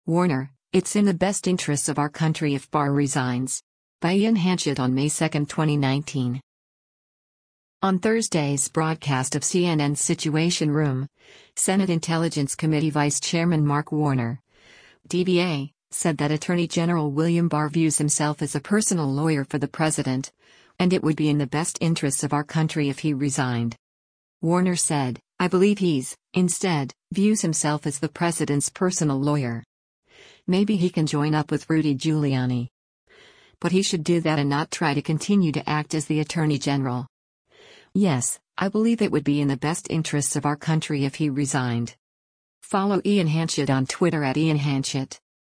On Thursday’s broadcast of CNN’s “Situation Room,” Senate Intelligence Committee Vice Chairman Mark Warner (D-VA) said that Attorney General William Barr views himself as a “personal lawyer” for the president, and “it would be in the best interests of our country if he resigned.”